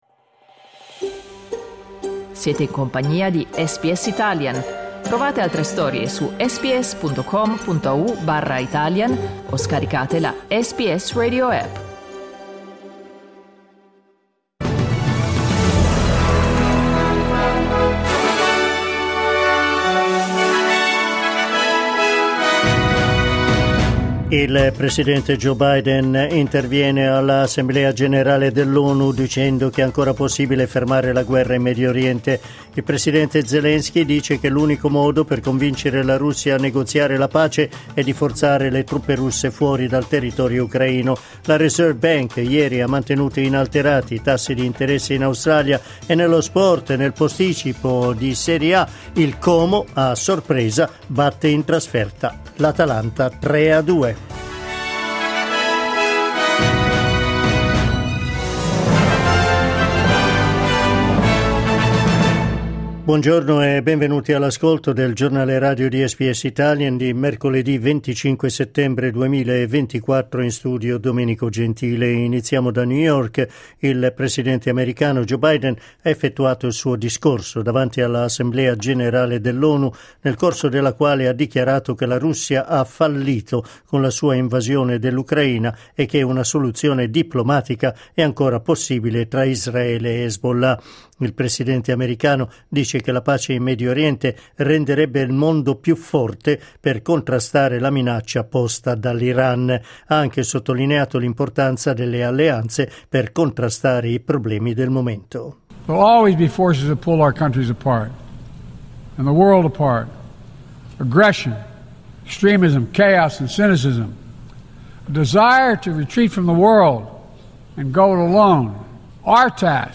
Giornale radio mercoledì 25 settembre 2024
Il notiziario di SBS in italiano.